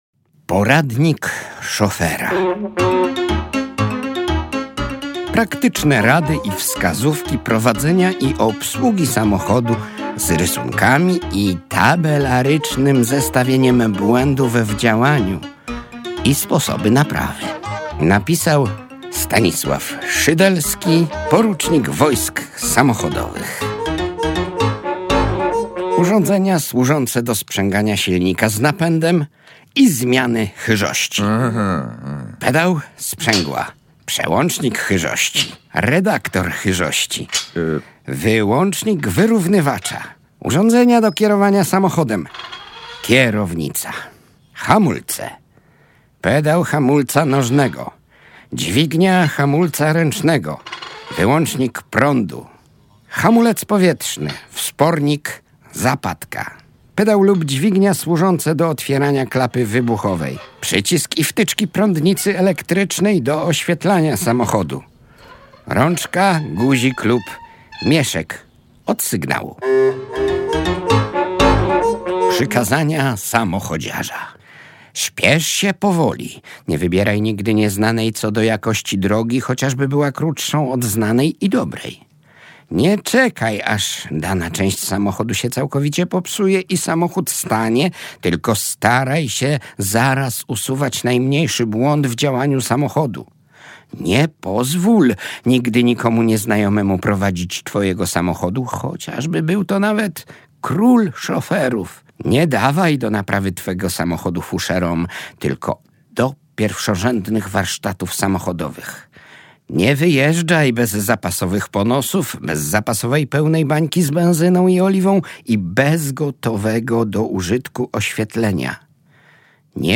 W programie słuchowisko na podstawie „Poradnika szofera” z 1921 roku, jak uruchomić samochód, dlaczego silnik można czyścić tylko jedwabiem i co oznacza tajemnicze „zapłon na popłon”?